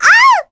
Cleo-Vox_Damage_kr_02.wav